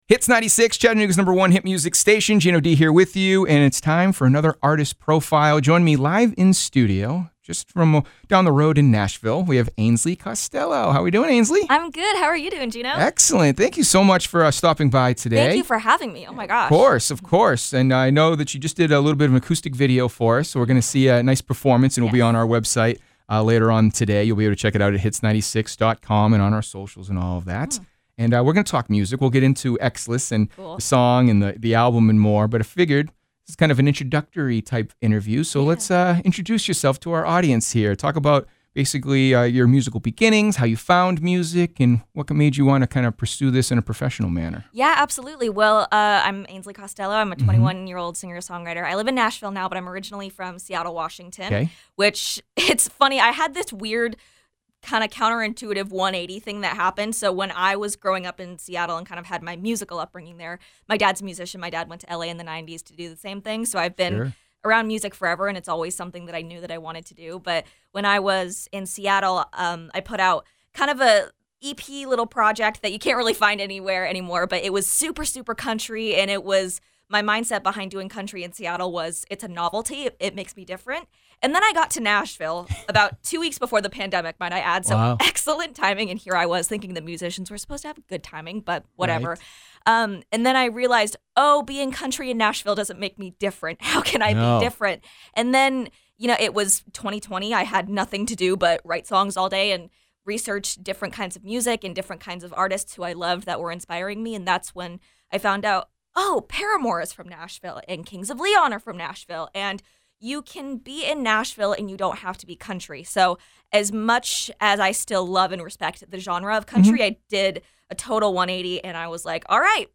Full-Interview-AC.mp3